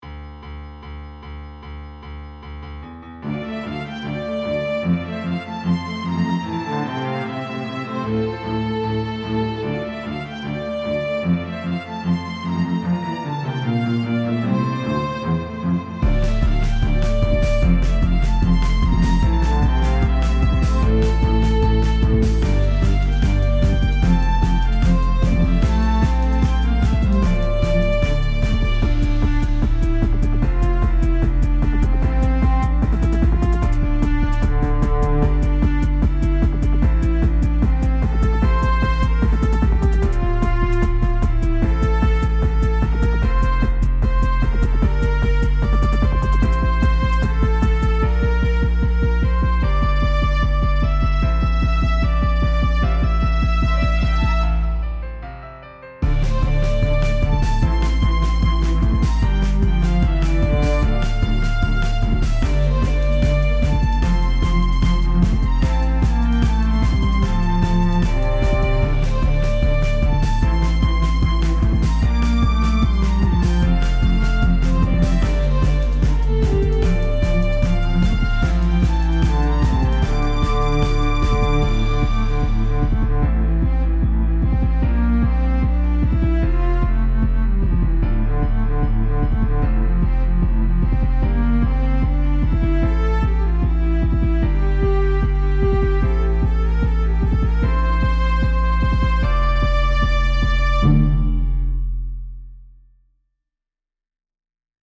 BGM エレクトロニカ